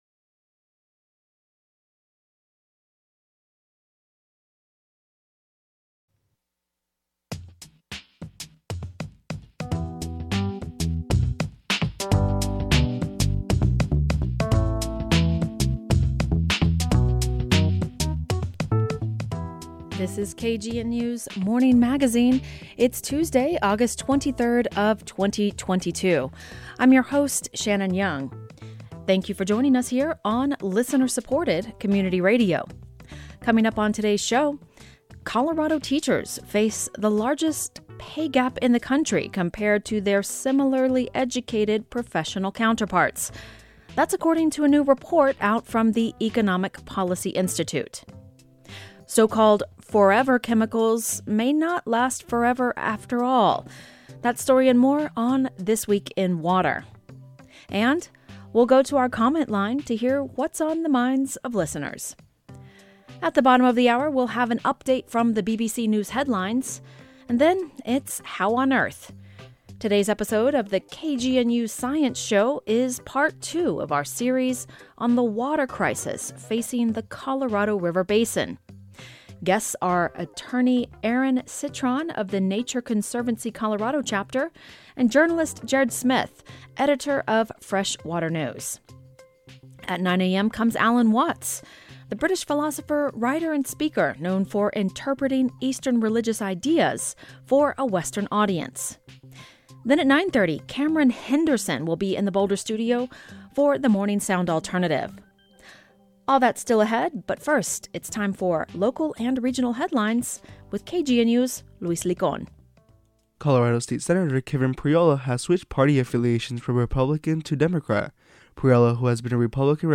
Then, we’ll go to our comment line to hear what’s on the minds of listeners.